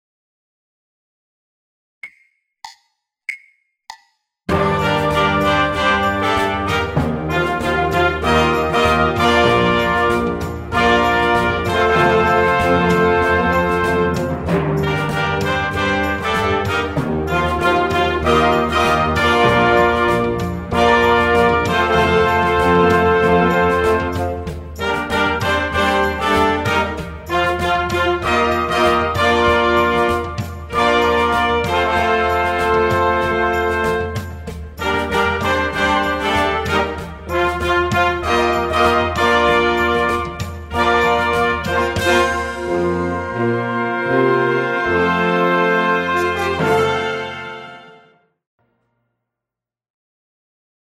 96 bpm